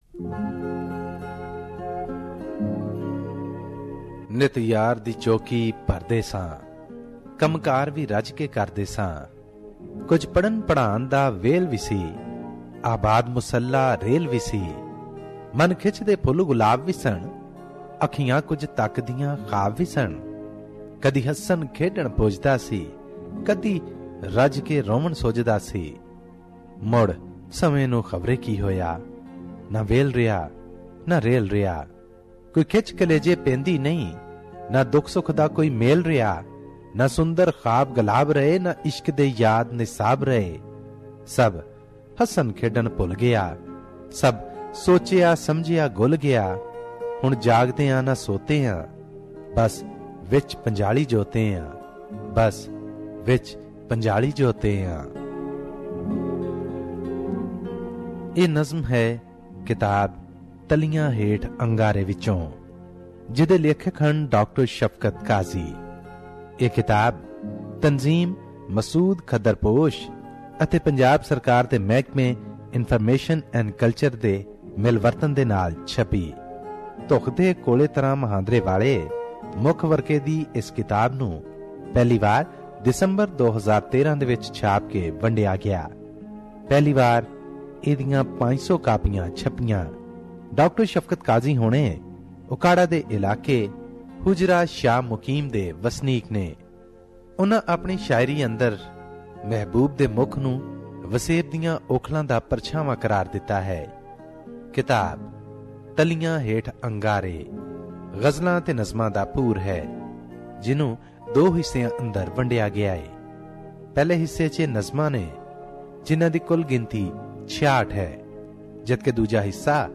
Ever wondered to know the basic difference between a Ghazal and a Nazam? Listen to this book review and you will notice a big difference between the two.